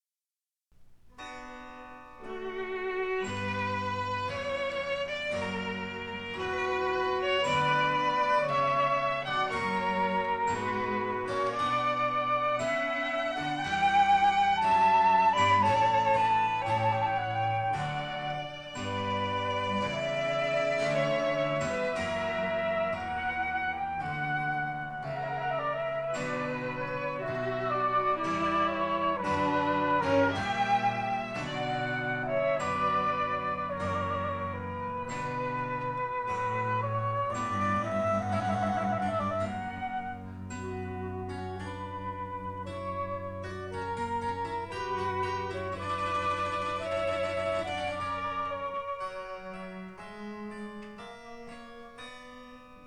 in D major
flute
violin
harpsichord
1960 stereo recording made by